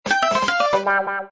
levelfail.mp3